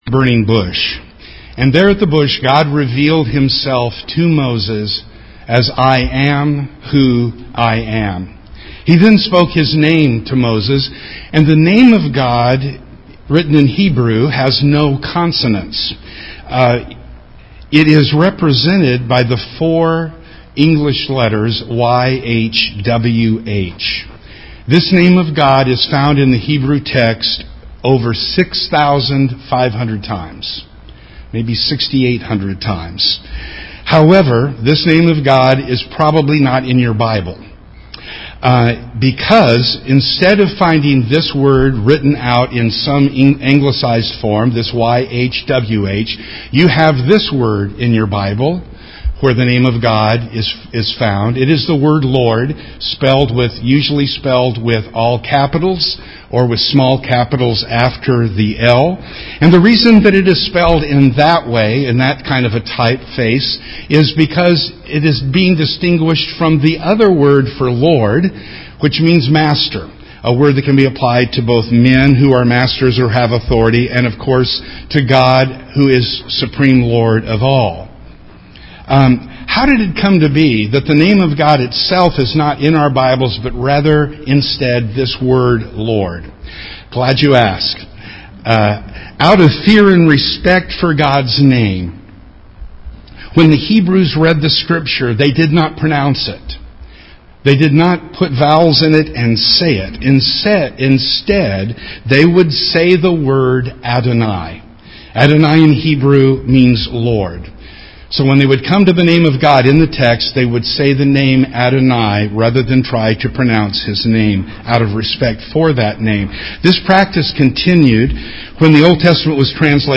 The scripture reading for this lesson was Exodus 6:2-5